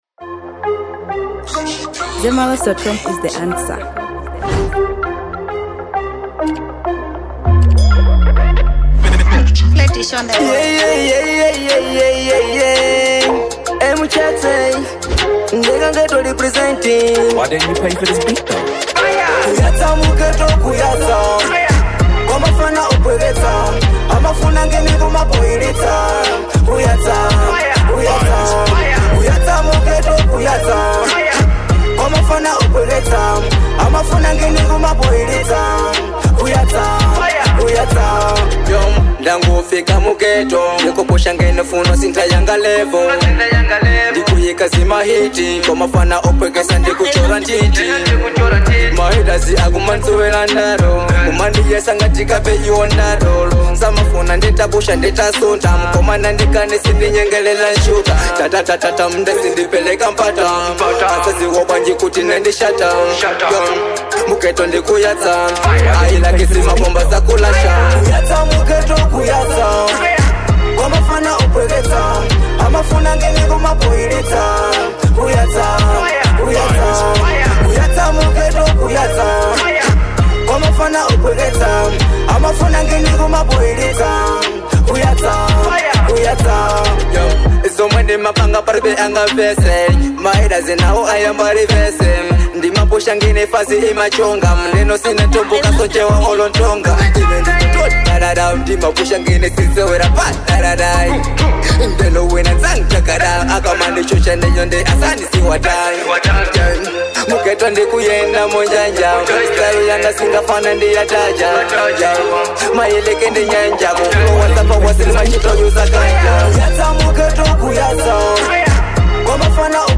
Genre: Dancehall.